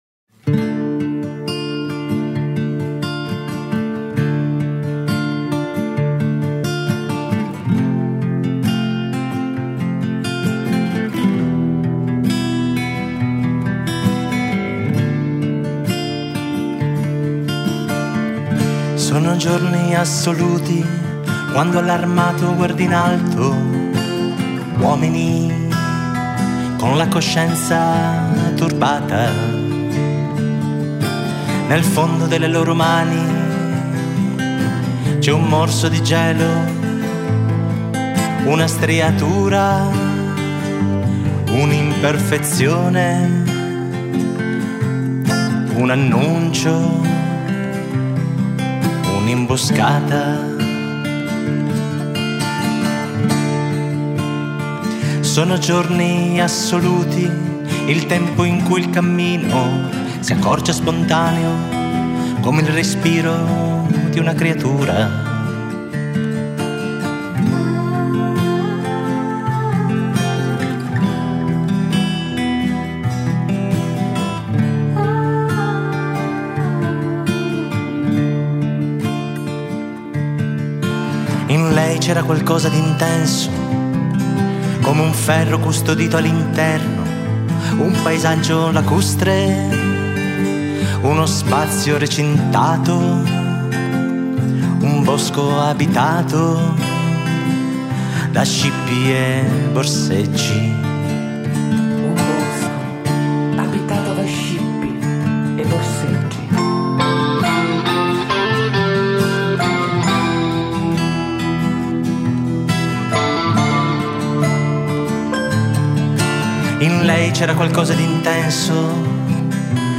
Un ambiente di riflessione e benessere impostato per intercettare risonanze emotive degli spettatori e offrire spunti di meditazione su determinati stati emotivi. Il box, immaginato come uno spazio di protezione, diffonde suoni, silenzio, voce e proiezioni multimediali; un luogo di raccoglimento e un tempo per vivere un’esperienza sensoriale.